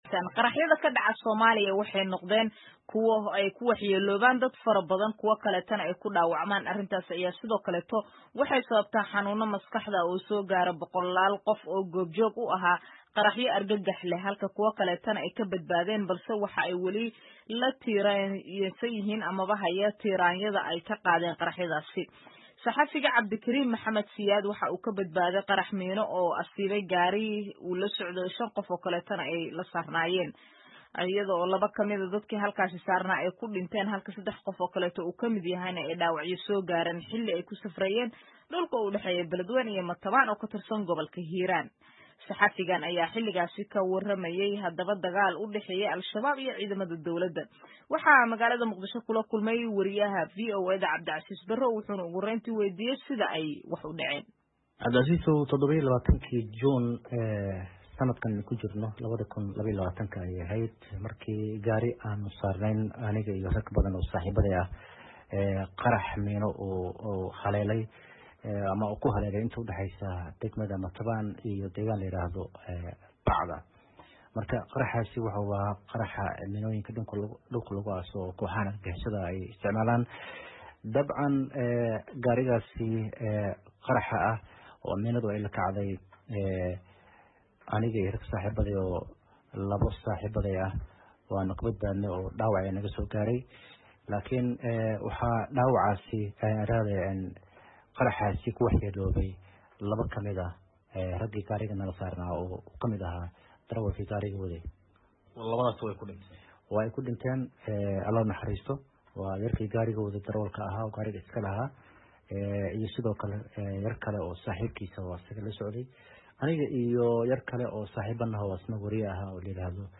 Wareysi: Waxyeellada caafimaad ee ay qaraxyadu ku reebeen suxufiyiinta Soomaaliyeed ee ka badbaaday